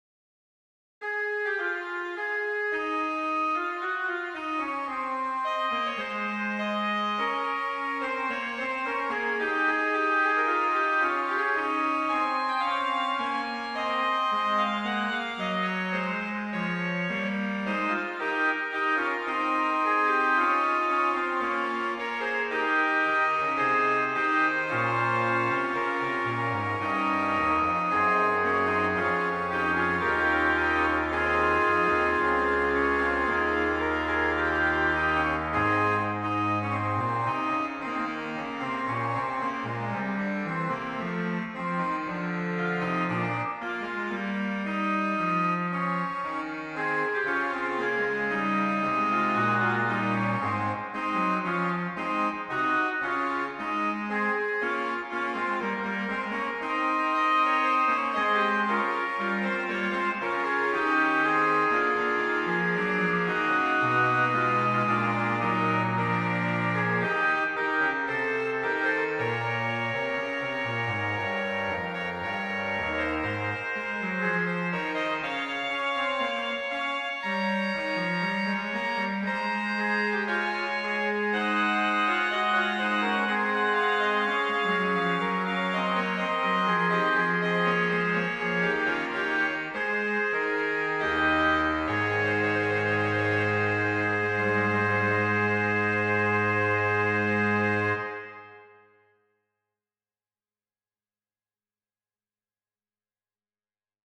無料1000MB 最近の画像 人気の画像 コメント閲覧 ログイン ユーザー登録 トップページ 古楽の部屋 大クープラン 修道院のミサ曲 トランペット管のフーガ（キリエ第２） 詳しく検索 修道院のミサ曲 1。